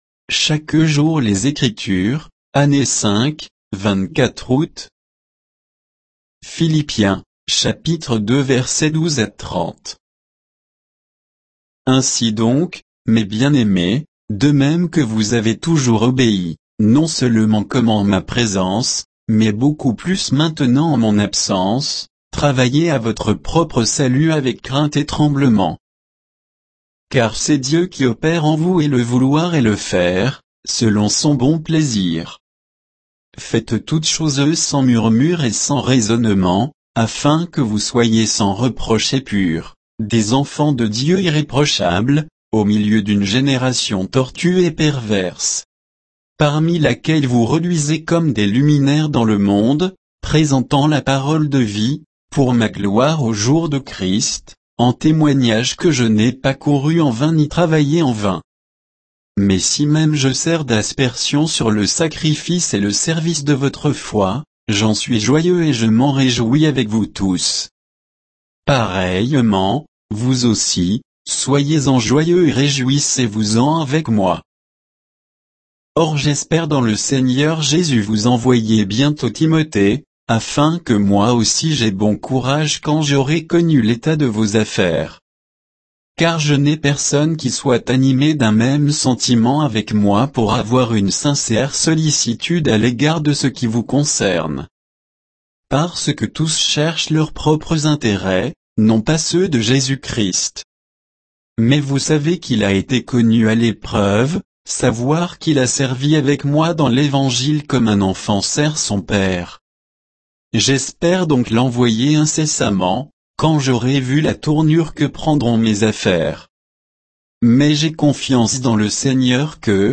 Méditation quoditienne de Chaque jour les Écritures sur Philippiens 2